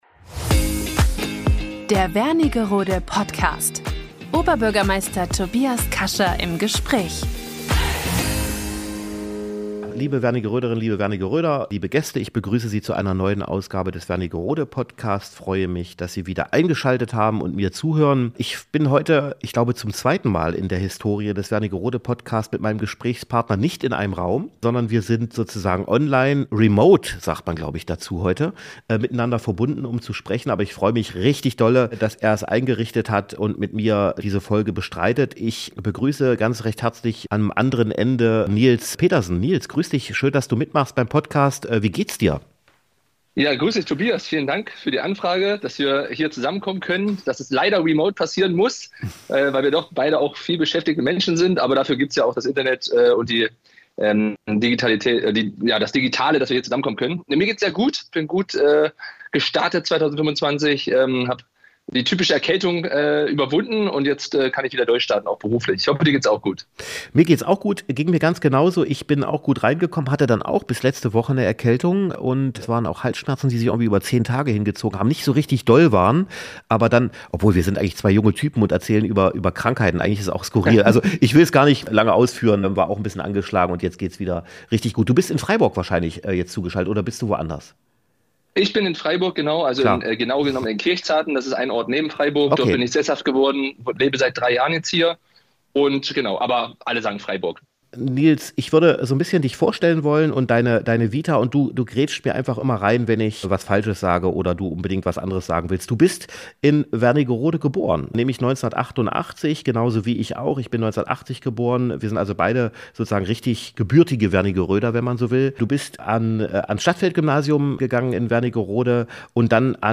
In der aktuellen Folge spricht Oberbürgermeister Tobias Kascha mit Nils Petersen, ein ehemaliger deutscher Profi - Fußballspieler, der in Wernigerode geboren wurde. Erfahre, wie seine Karriere begann, welche Erinnerungen er an seine Heimat hat und was ihn heute antreibt.